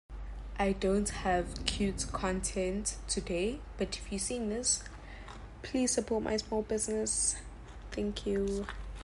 The Ultimate Clay Crunch Experience sound effects free download
# claycrunch # asmr # edibleclay # satisfying